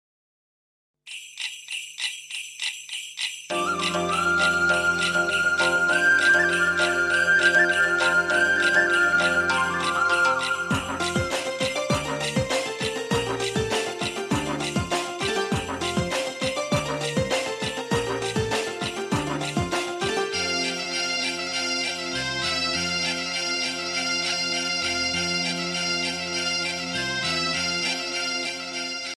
tamil ringtoneemotional ringtonelove ringtonemelody ringtone
best flute ringtone download